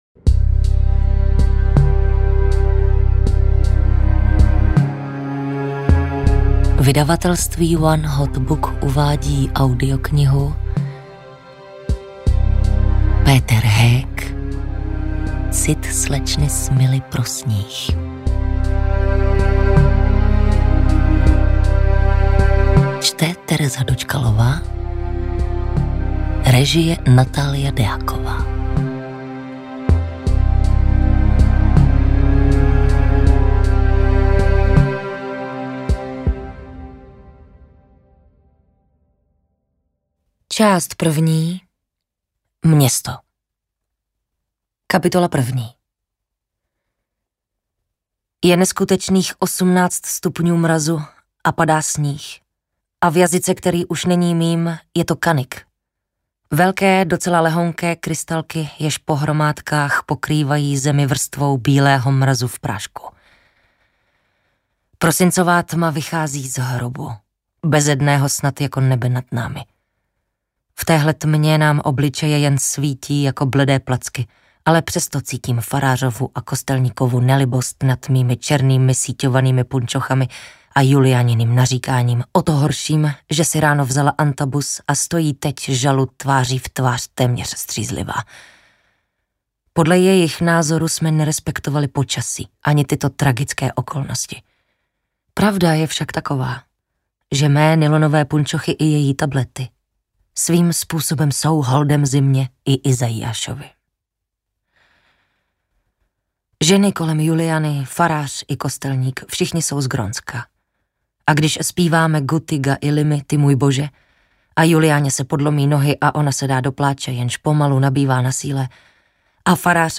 AudioKniha ke stažení, 35 x mp3, délka 16 hod. 59 min., velikost 925,0 MB, česky